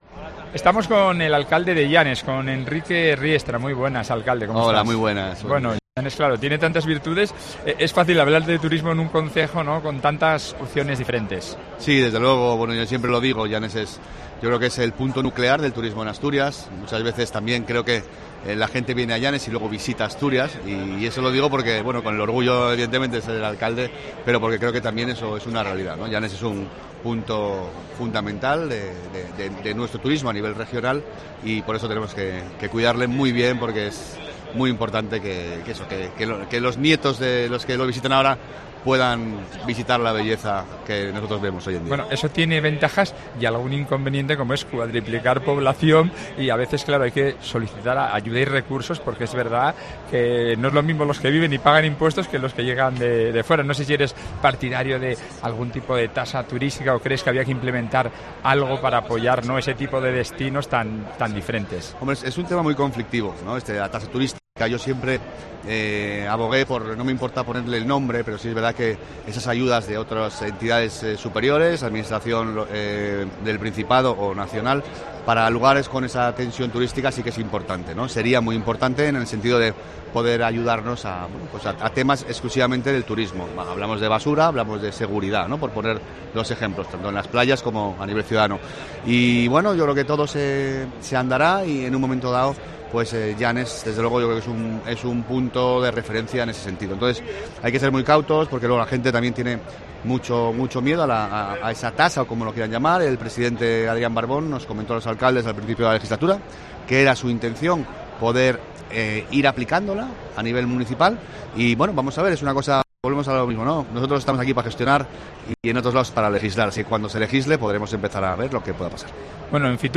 En el especial de COPE Asturias desde IFEMA con motivo de FITUR 2024, hablamos con el alcalde del concejo, Enrique Riestra
El alcalde de Llanes explica en COPE las apuestas que el concejo lleva a FITUR